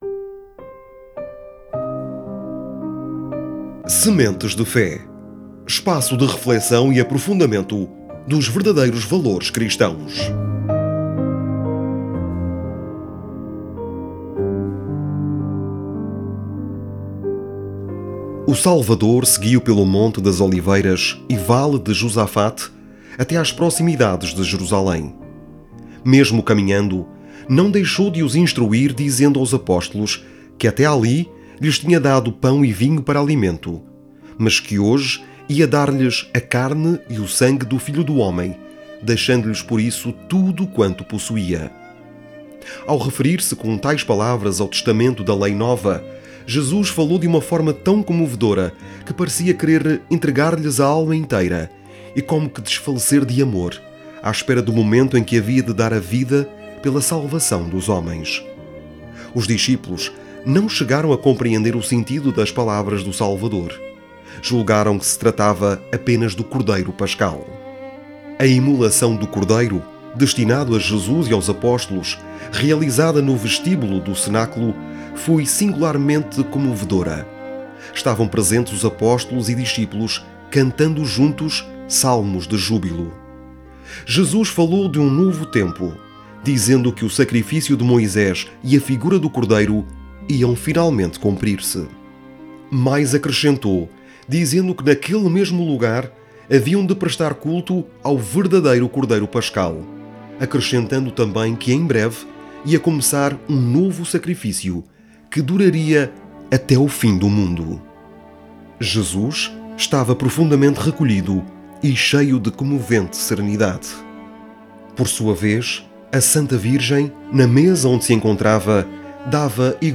Um extracto do livro "A Paixão de Cristo"